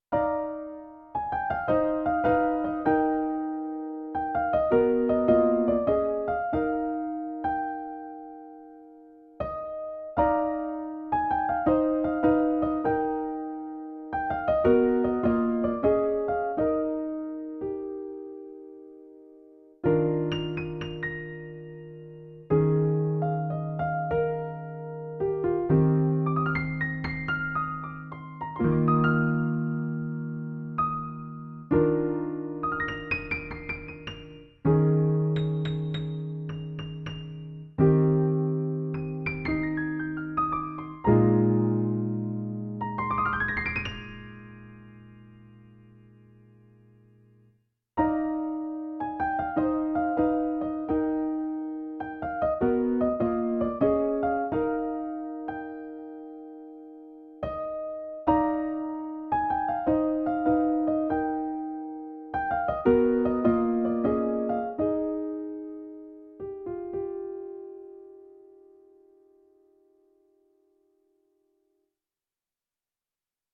a short piano piece